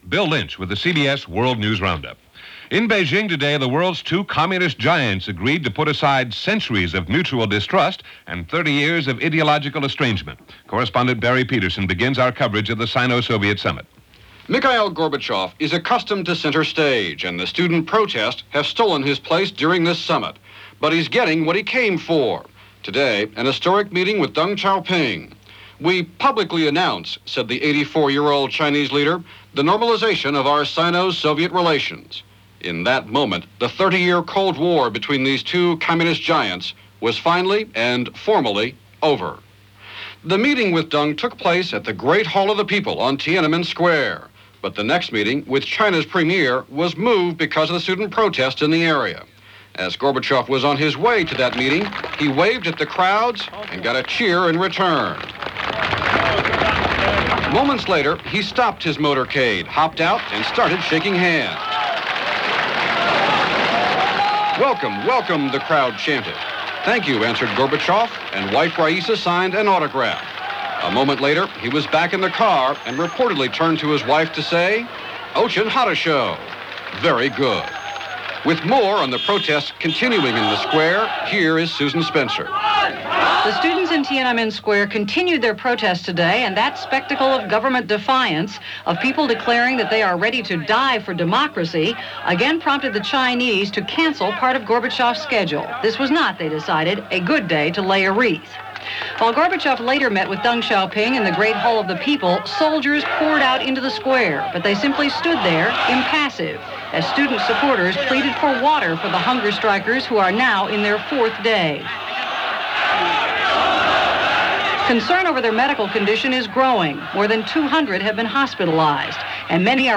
There was no doubt much else going on, but the happenings in Tiananmen Square were capturing attention all over for this May 16, 1989 as reported by The CBS World News Roundup.